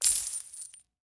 coin_large.wav